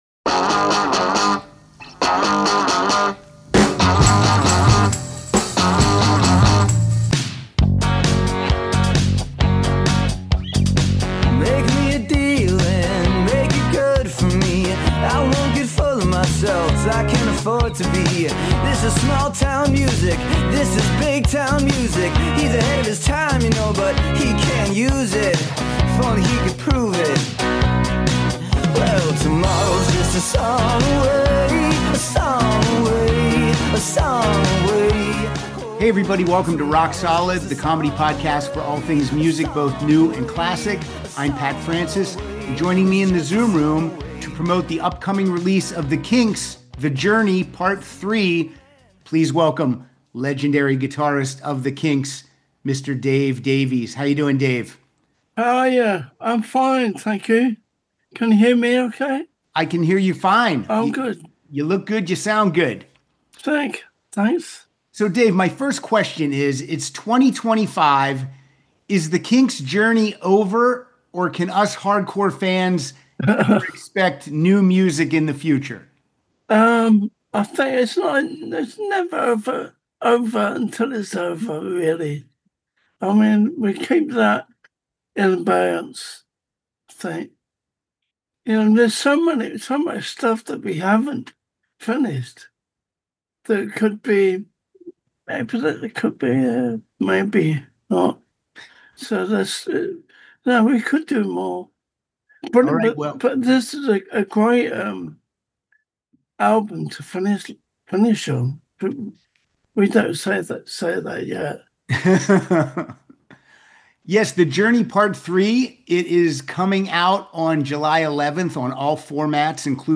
welcomes legendary guitarist Dave Davies to the Zoom Room to discuss his career in music and promote the new Kinks collection "The Journey: Part 3."